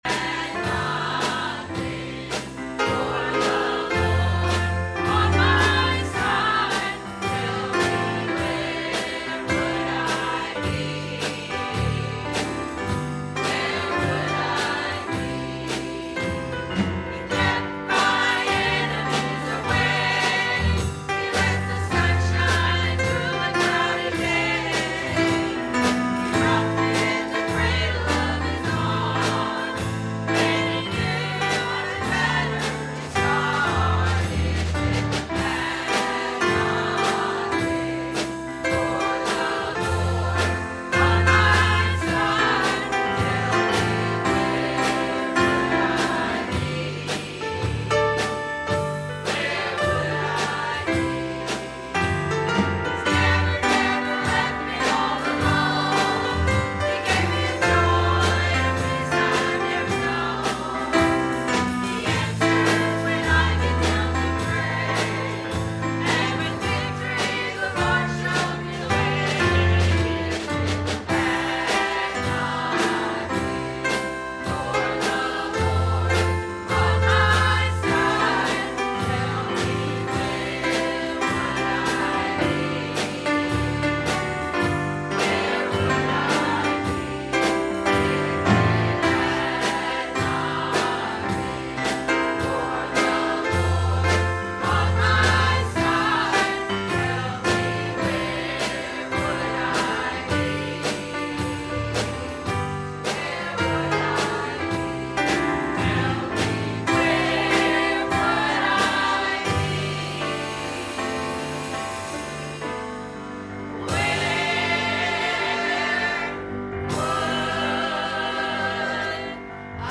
Series: Message
Proverbs 31:10 Service Type: Sunday Morning « A Glimpse Of Grace